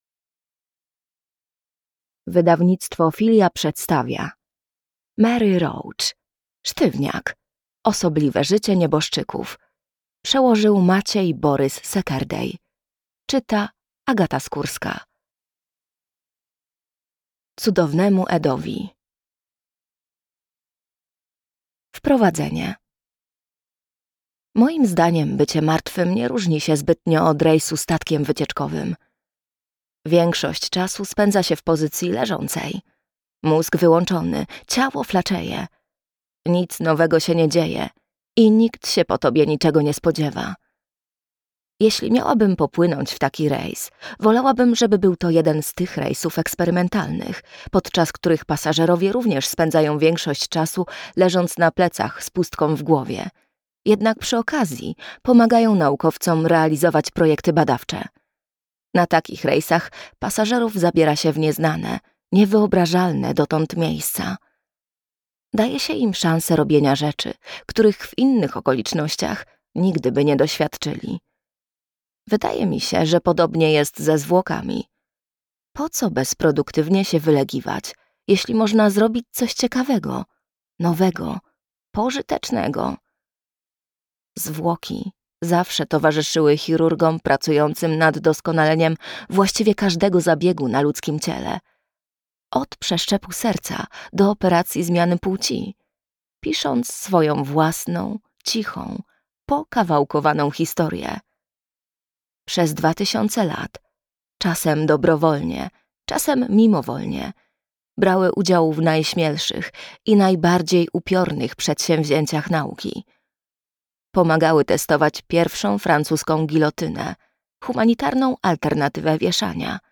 Sztywniak - Mary Roach - audiobook